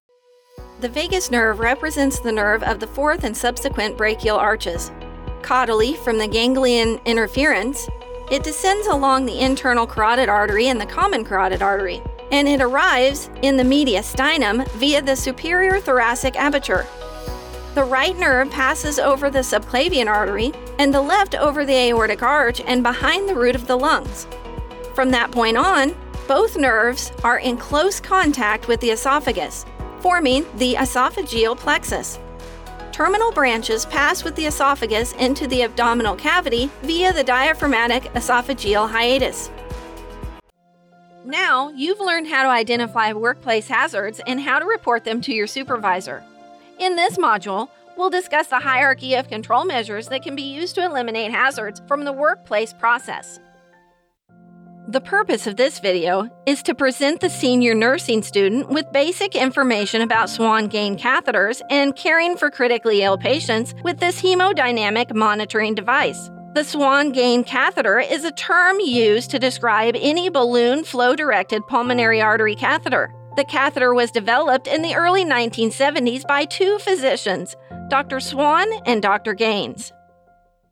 Professional Female Midwest American accent
Demo Reel